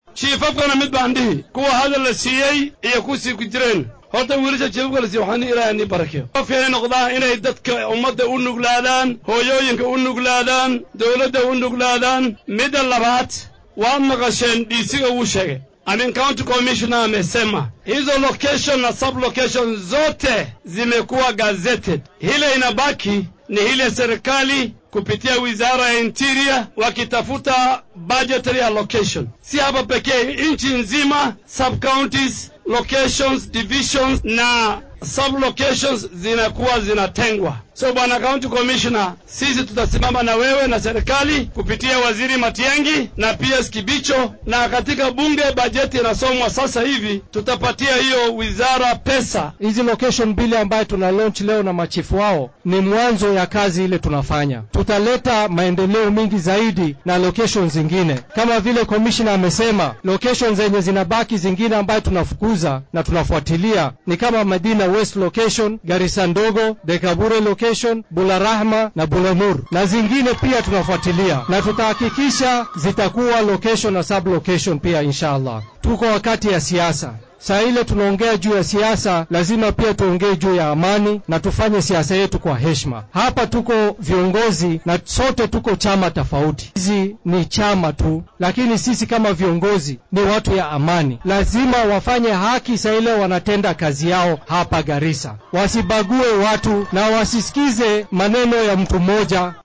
Dowladda dhexe ayaa xaafadda Kunaso ee ka tirsan deegaanka hoose ee Waaberi ee magaalada Garissa oo ay horay falal amni darro uga dhaceen u aqoonsatay inay tahay tuulo rasmi ah. Waxaa xafladda goobjoog ka ahaa xildhibaanka Garissa Township Aadan Barre Ducaale ,senatarka ismaamulkaasi Cabdiqaadir Maxamad Yuusuf , barasaabkii hore ee Garissa Nadiif Jaamac iyo guddoomiyaha dowladda dhexe ku matalo ismaamulkaasi Mr. Boaz Cherotich. Mas’uuliyiintan ayaa guddoomiye xaafadeedka Kunaso iyo saraakiisha amniga ugu baaqay inay waajibaadkooda shaqo si cadaalad ah uga soo baxaan.